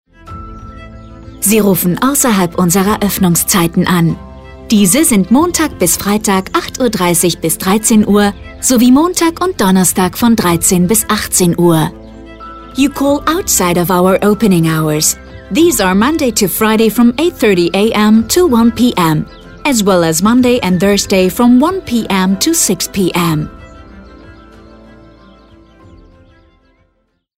AB Ansage ausserhalb der Geschäftszeiten
Rathaus-Ansage-ausserhalb-der-GSZ-1a.mp3